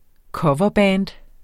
Udtale [ ˈkʌvʌˌbæːnd ]